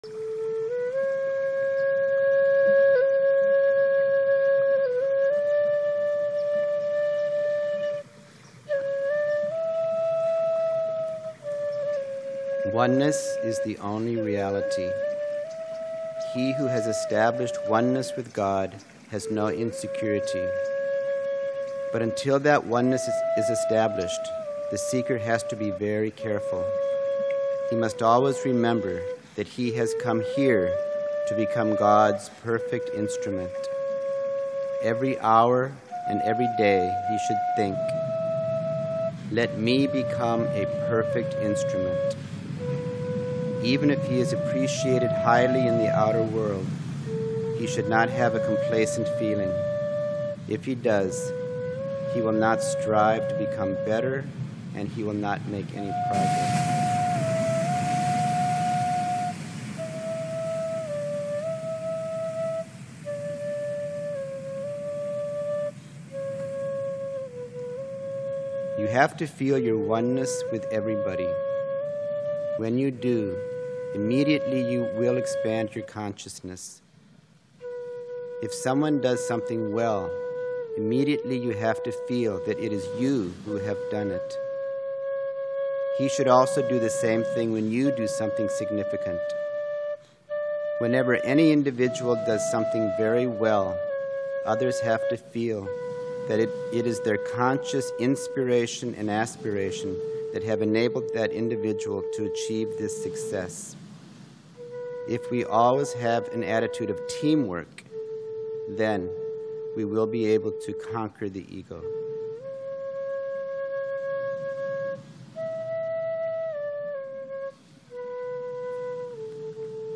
Readings from Sri Chinmoy’s writings, by his students | Radio Sri Chinmoy
When Sri Chinmoy’s students meet together for meditations, we often like to read aloud from his writings; these recordings are taken from some of those readings.